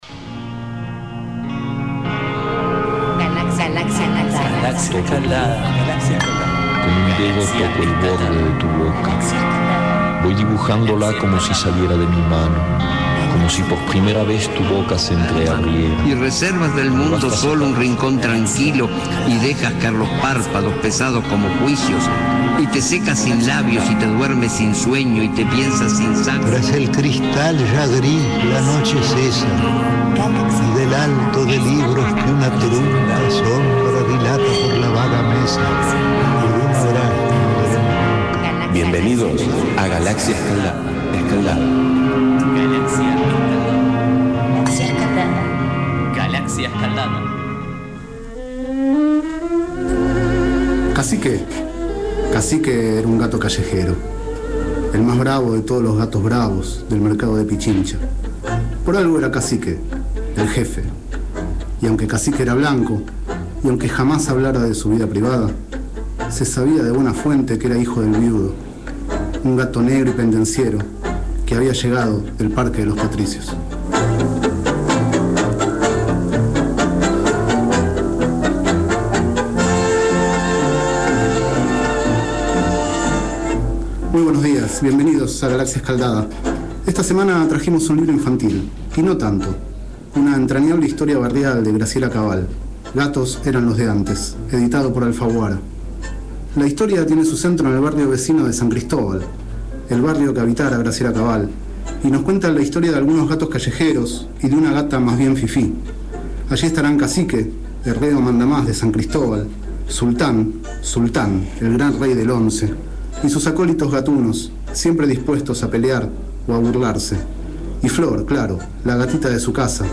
3º micro radial, realizado el 19 de marzo de 2012, sobre el libro Gatos eran los de antes, de Graciela Cabal.